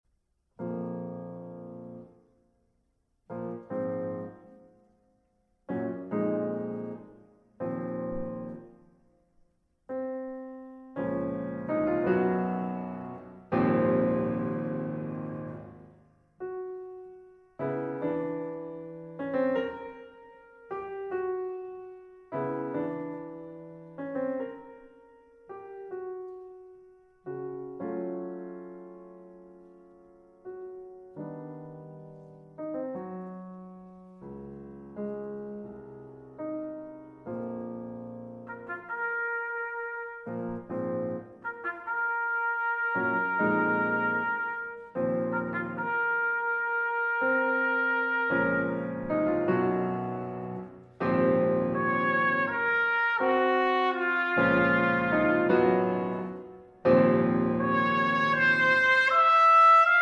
trumpet and alto horn